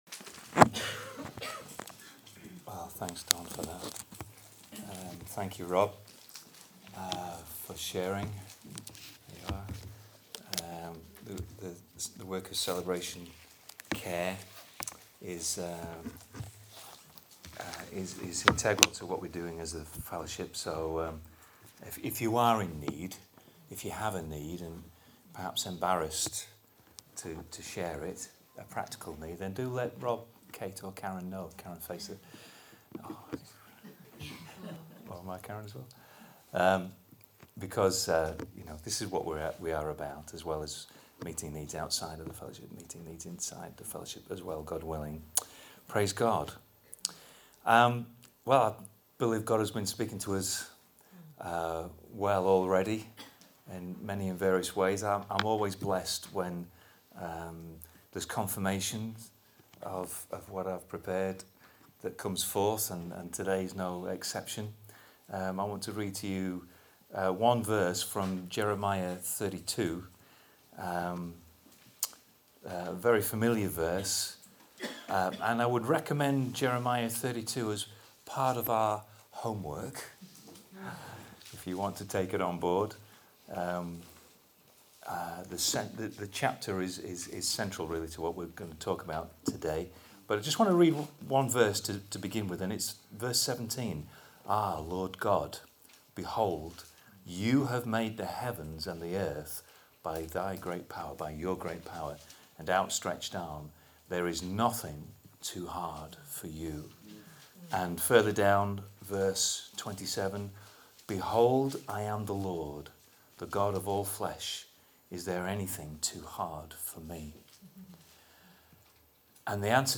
Below are the recordings from this morning’s service.